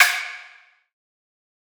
Clap [Graduation].wav